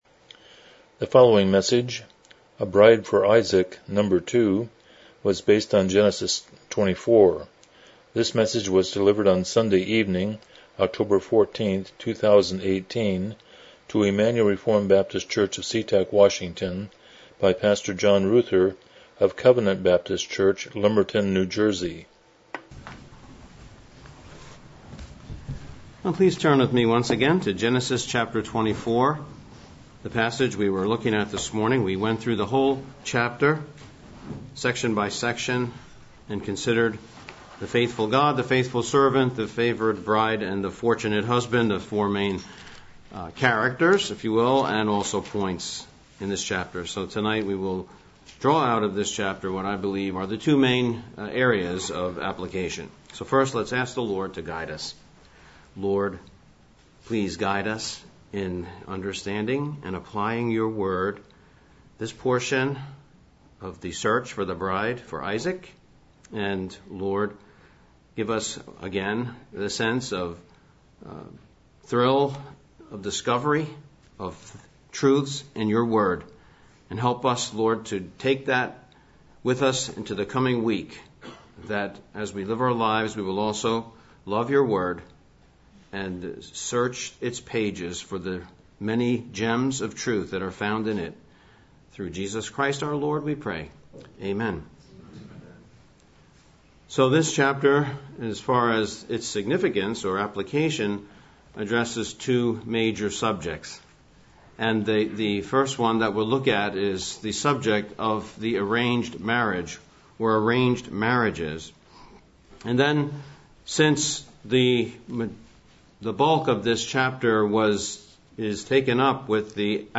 Genesis 24:1-67 Service Type: Evening Worship « A Bride for Isaac